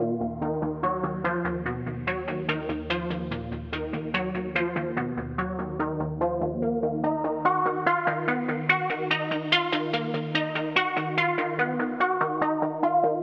蔑视这一点 Arp 145bpm
描述：陷阱和科幻的结合。沉重的打击和神秘感。
Tag: 145 bpm Trap Loops Synth Loops 2.23 MB wav Key : D